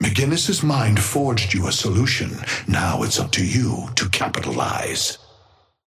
Amber Hand voice line - McGinnis' mind forged you a solution.
Patron_male_ally_ghost_oathkeeper_5a_with_mcg_start_01.mp3